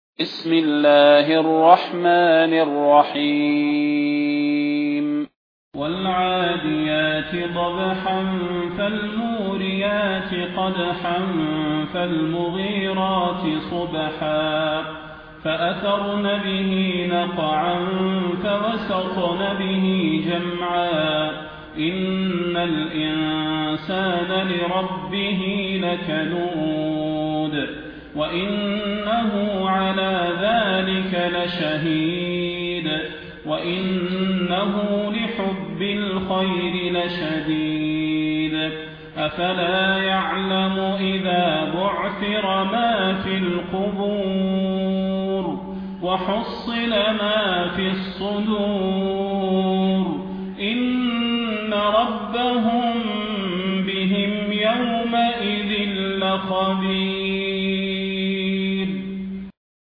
المكان: المسجد النبوي الشيخ: فضيلة الشيخ د. صلاح بن محمد البدير فضيلة الشيخ د. صلاح بن محمد البدير العاديات